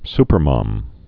(spər-mŏm)